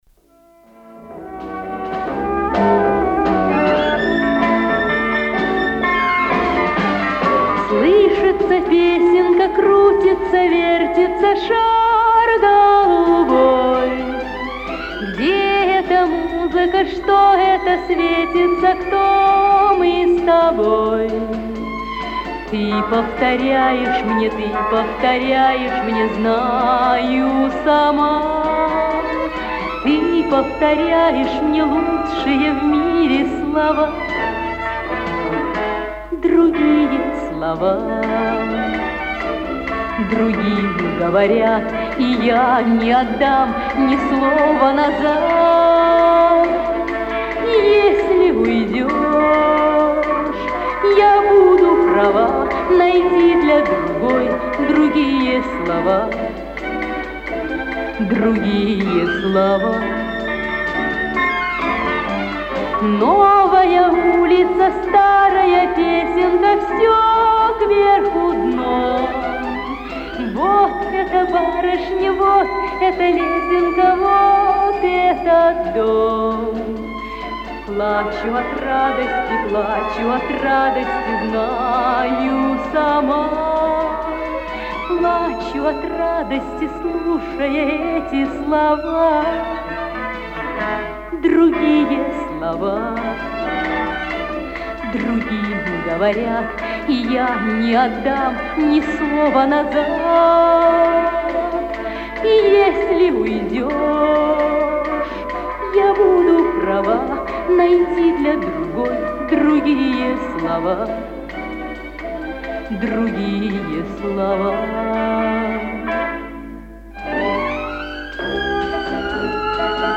Записи эфирные с радио